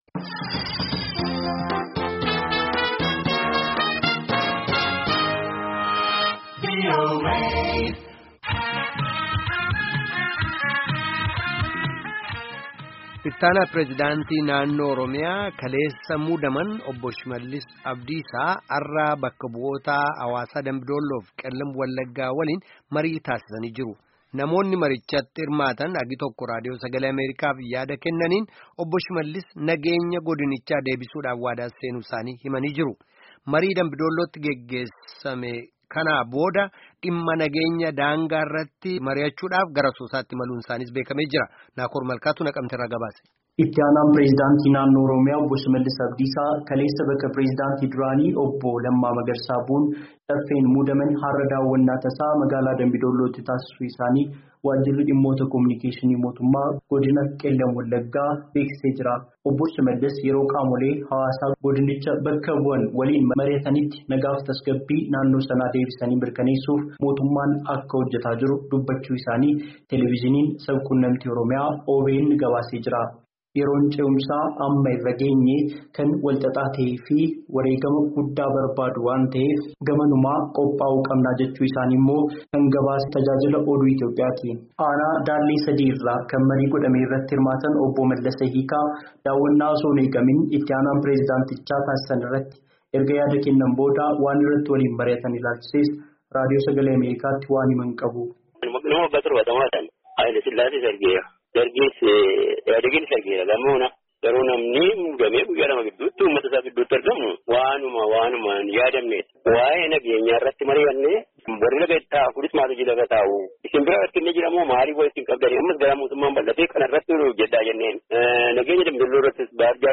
Namootni marichatti hirmaatan hagi tokko Raadiyoo Sagalee Ameerikaaf yaada kennaniin, Obbo Shimallis nageenya godinichaa deebisuuf waadaa seenuu isaanii ibsan.